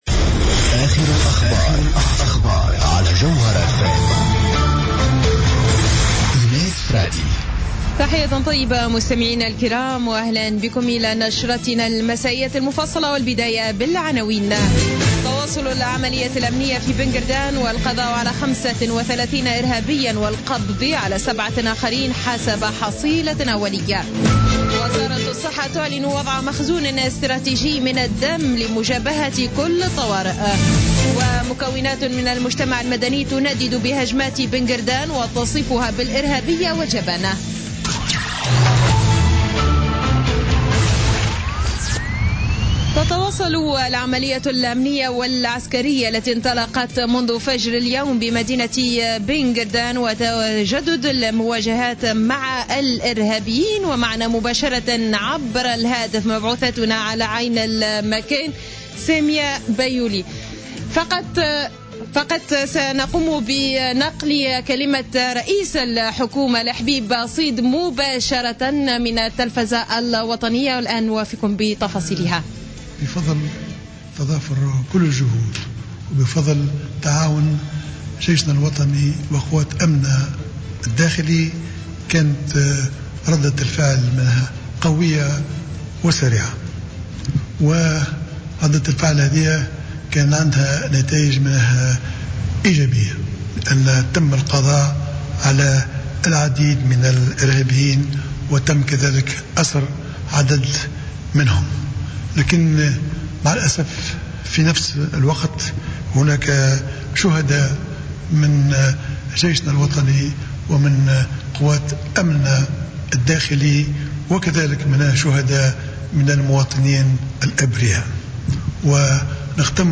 نشرة أخبار السابعة مساء ليوم الاثنين 7 مارس 2016